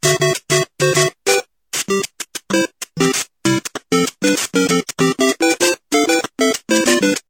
عينة موسيقية